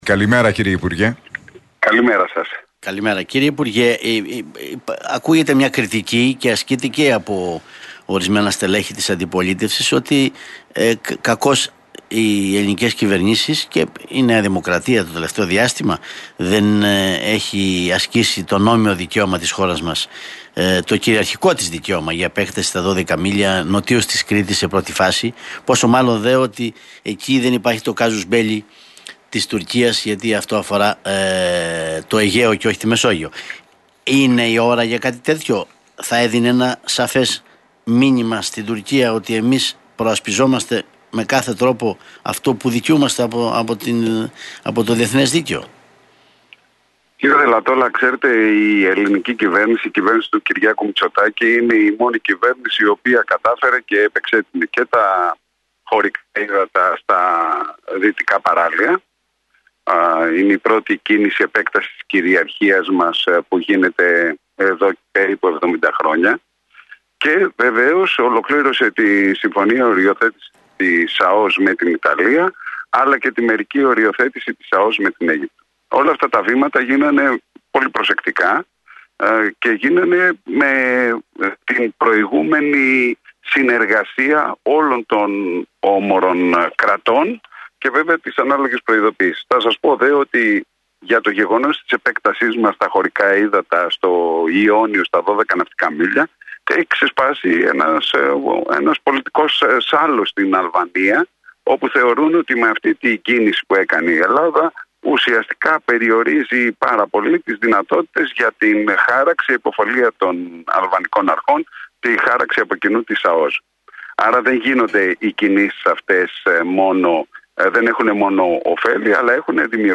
Στις τουρκικές προκλήσεις αναφέρθηκε ο αναπληρωτής υπουργός Εξωτερικών Μιλτιάδης Βαρβιτσιώτης σε συνέντευξη στον Realfm 97,8